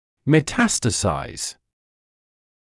[mə’tæstəsaɪz][мэ’тэстэсайз]метастазировать